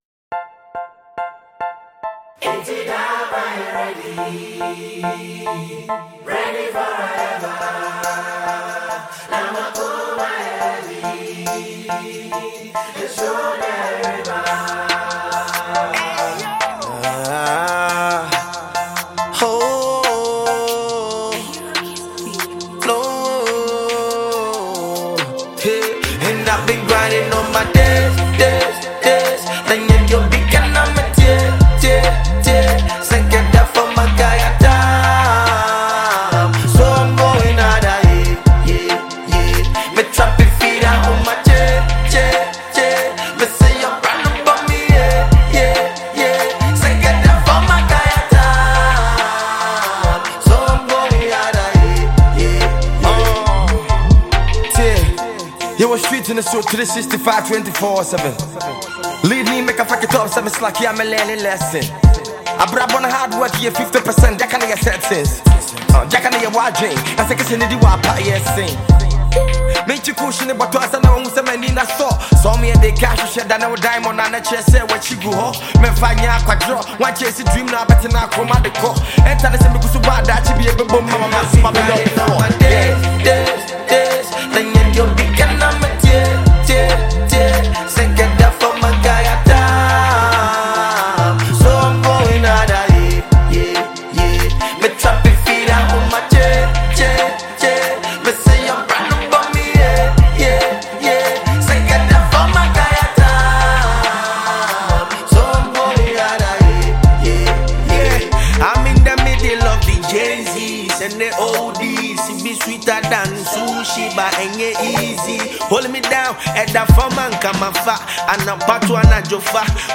Award-winning Ghanaian rapper
With its infectious beat, catchy hook, and powerful message